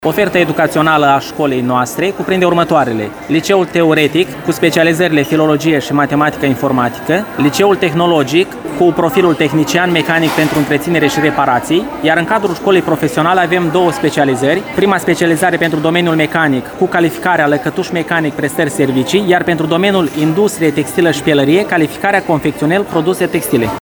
Centrul Judeţean de Resurse şi Asistenţă Educaţională Suceava a organizat astăzi, la Shopping City, Târgul Ofertelor Educaționale.